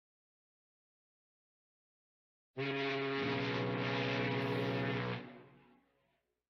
Arquivo:Buzinadeneblina-audio.ogg